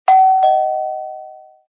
Doorbell.mp3